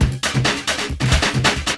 14 LOOP10 -L.wav